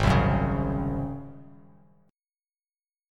Ab11 chord